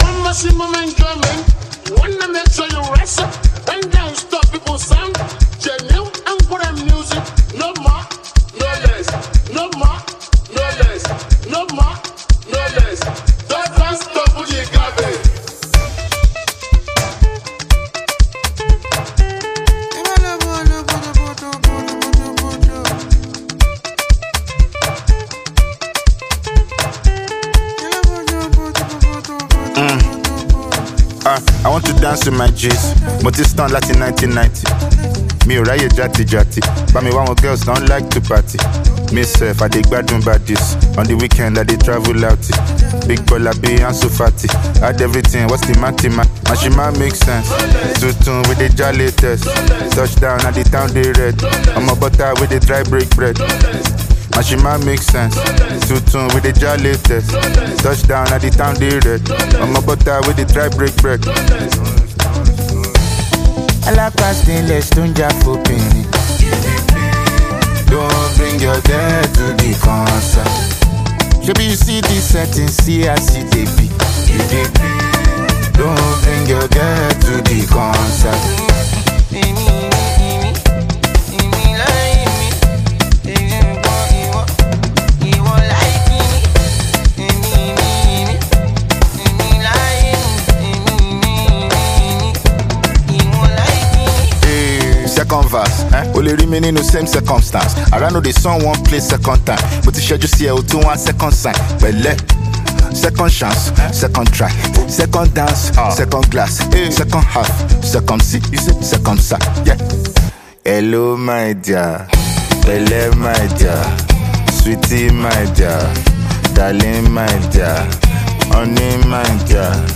Nigerian rapper and singer
offering a mix of Afrobeat, hip-hop, and highlife sounds.